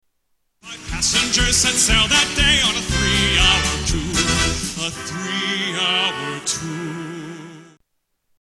TV Theme Songs Music